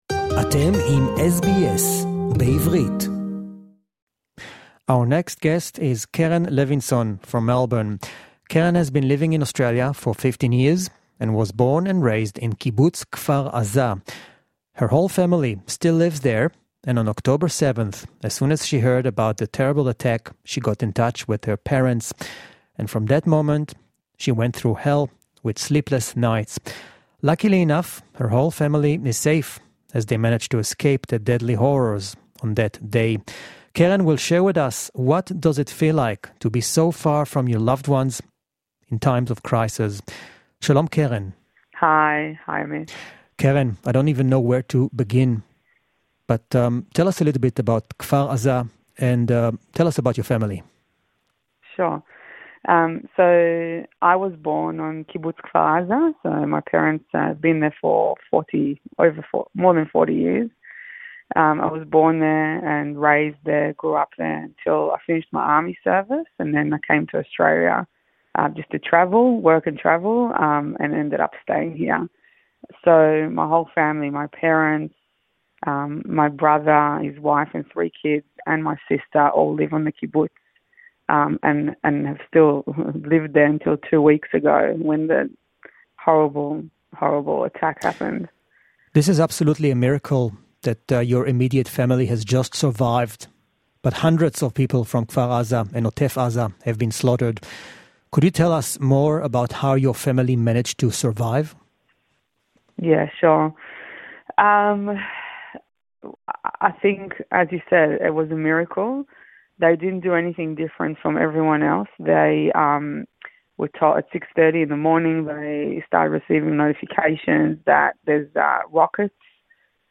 (English Interview)